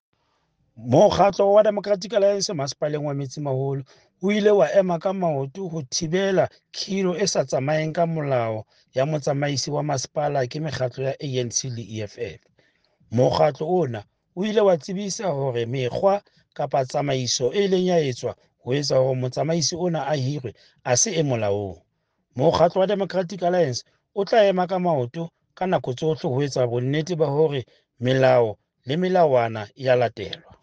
Sesotho soundbites by Cllr Stone Makhema and Afrikaans soundbite by Cllr Teboho Thulo.